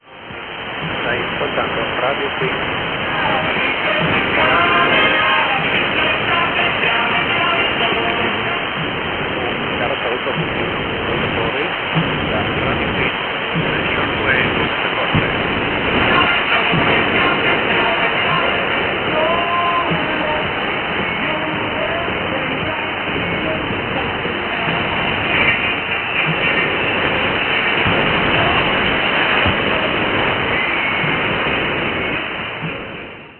6250 kHz - Radio Friends, Italy. Played a lot of 80's hits in a row.